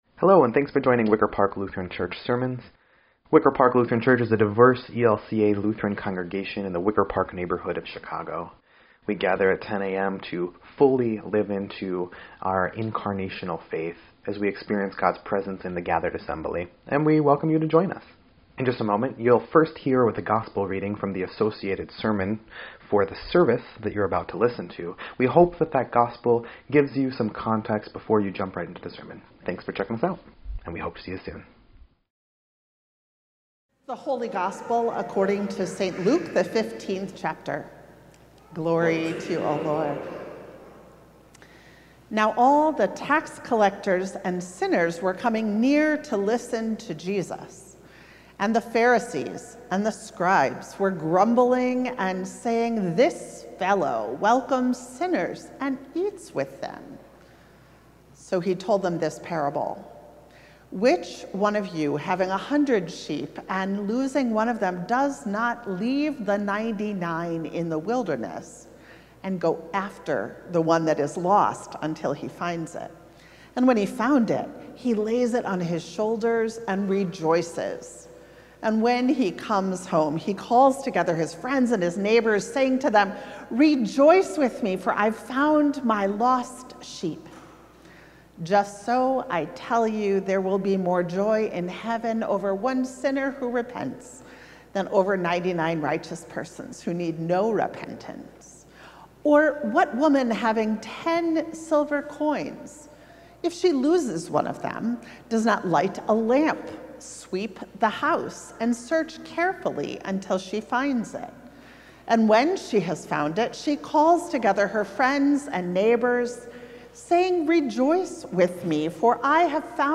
Wicker Park Lutheran Church
9.14.25-Sermon_EDIT.mp3